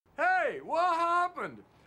hey wha sound effects